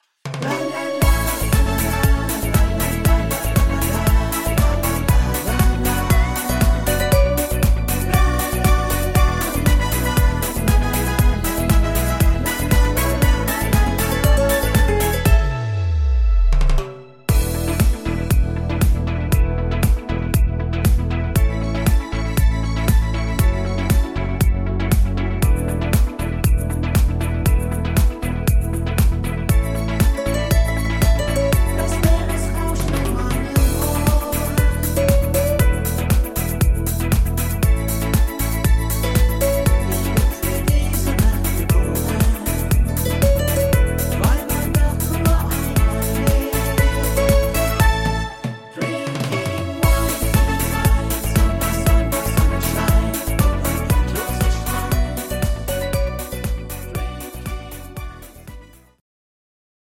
das gibt eine Stimmungsbombe